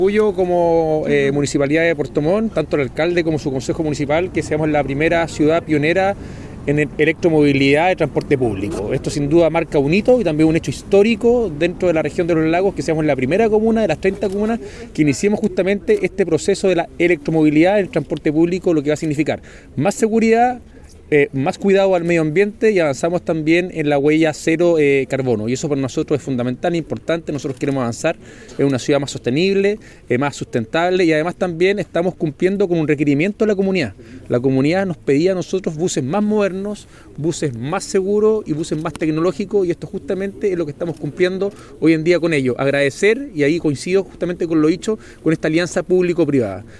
Por su parte, el alcalde Rodrigo Wainraihgt resaltó el impacto histórico de este hito al ser la primera comuna de la región y la más austral del país al contar con esta nueva tecnología.